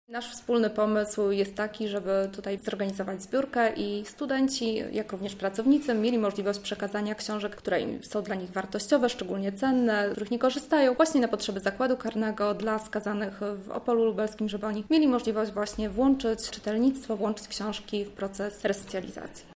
O akcji mówi